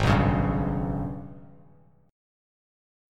G#mM11 chord